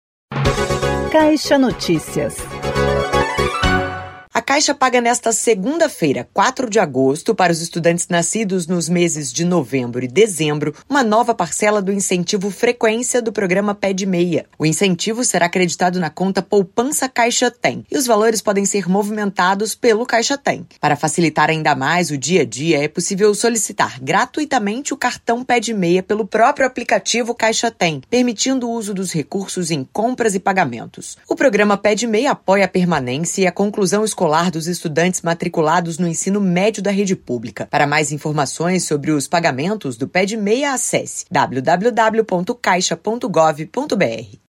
[SONORA]: CARLOS VIEIRA, PRESIDENTE DA CAIXA